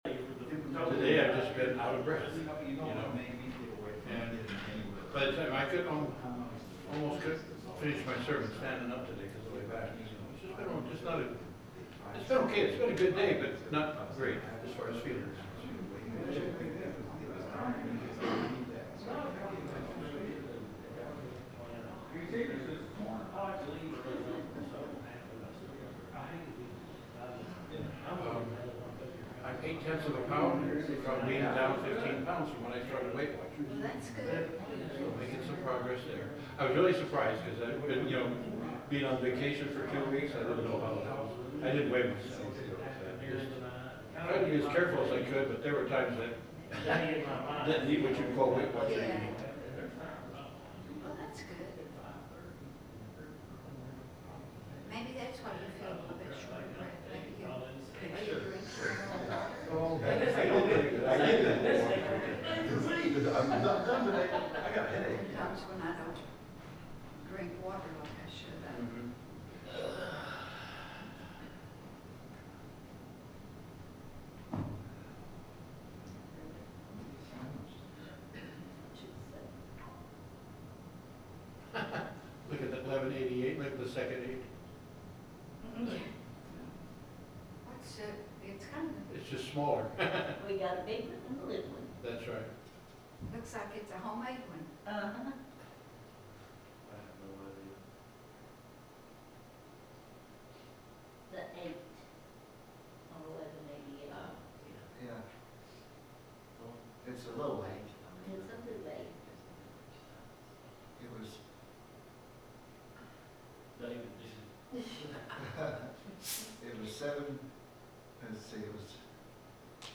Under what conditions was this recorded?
The sermon is from our live stream on 8/3/2025